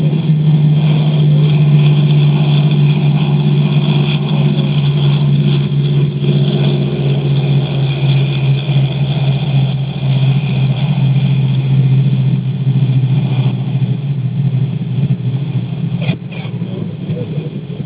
DC-3 Sound Files
Landing including main wheel screech (recorded from outside).